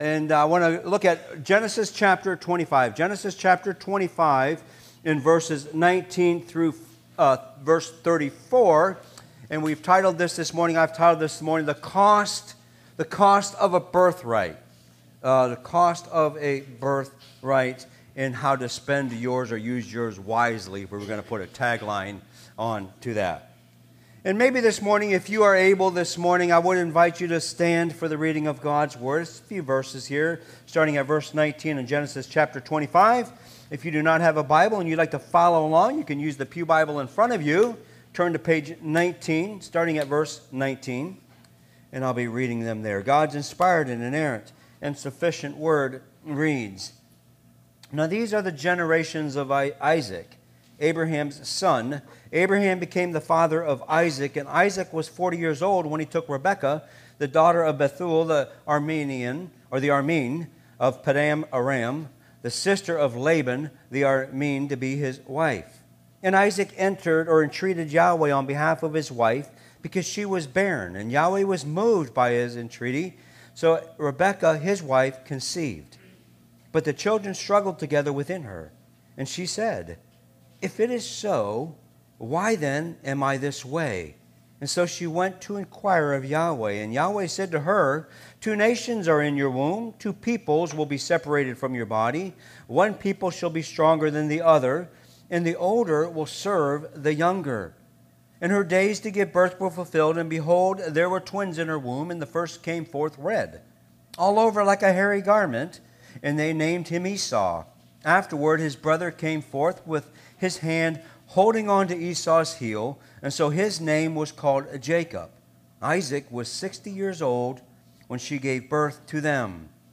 Teachings from Holly Grove Mennonite Church in Westover, Maryland, USA.
Holly Grove Sermons